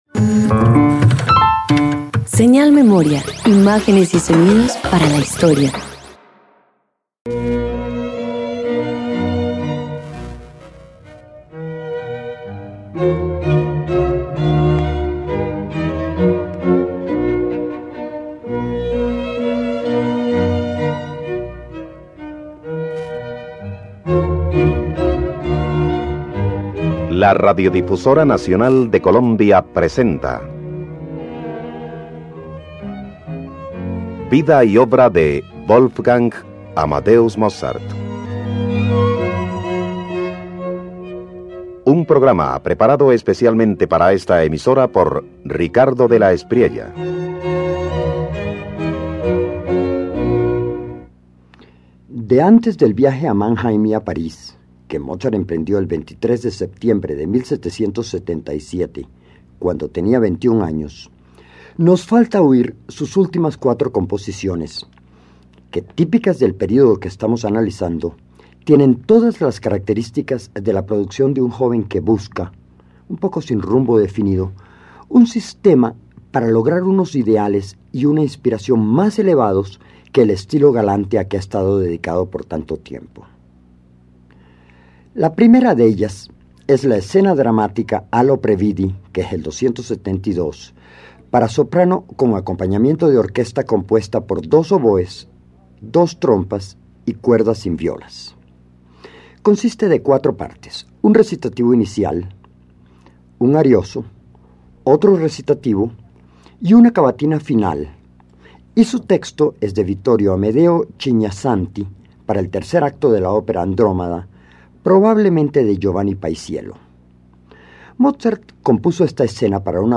En 1777, Wolfgang Amadeus Mozart compone Ah, lo previdi K272, una escena dramática para soprano y orquesta intensa y emotiva que revela la búsqueda de nuevos ideales, además de la madurez artística y sentimental del joven compositor.